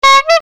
LFS Forum - New horns